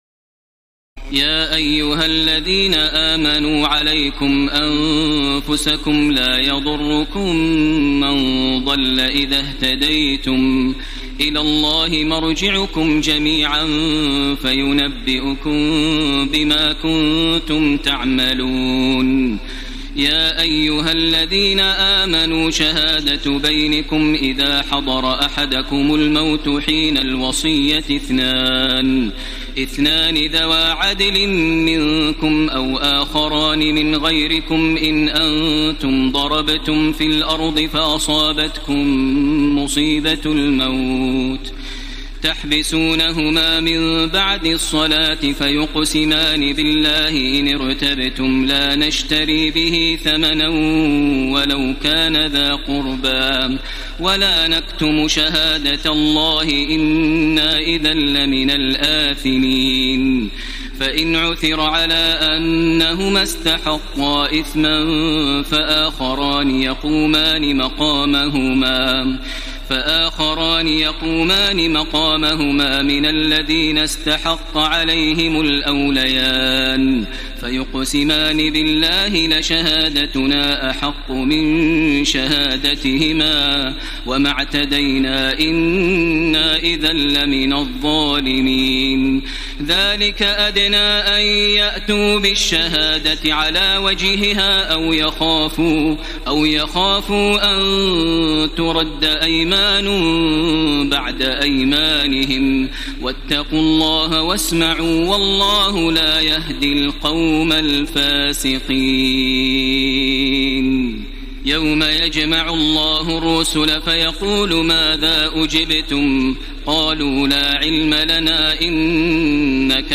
تراويح الليلة السادسة رمضان 1434هـ من سورتي المائدة (105-120) و الأنعام (1-73) Taraweeh 6 st night Ramadan 1434H from Surah AlMa'idah and Al-An’aam > تراويح الحرم المكي عام 1434 🕋 > التراويح - تلاوات الحرمين